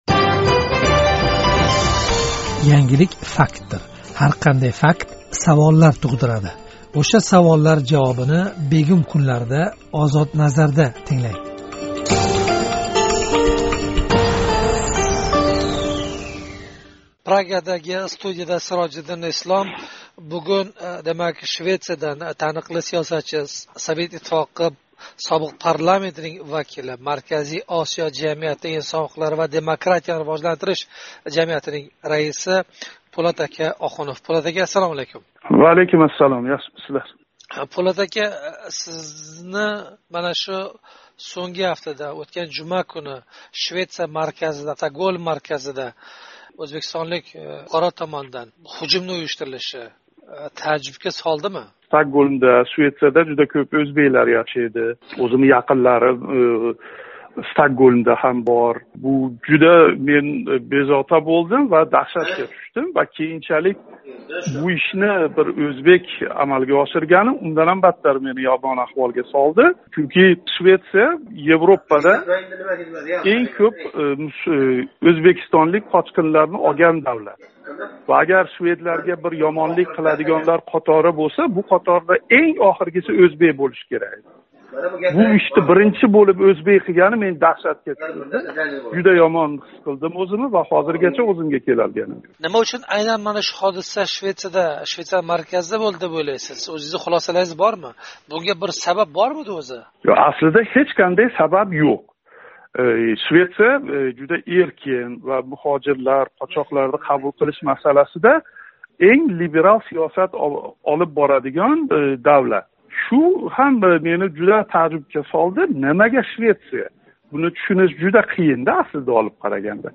Собиқ СССР халқ депутати Пўлат Охунов билан суҳбат